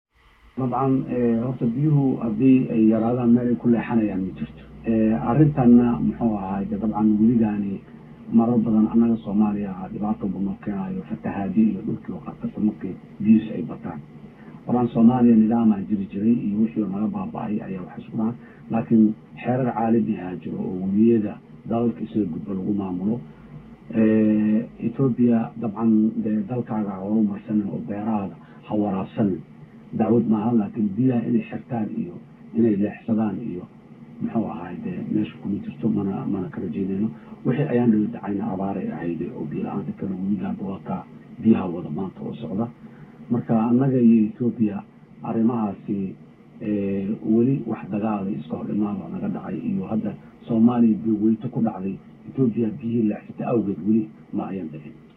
MAQAL: Xasan Sheekh oo ka hadlay biyaha wabiga shabeelle iyo Ahlu sunna oo ka naxay in Xasan Sheekh markale ku soo noqdo xukunka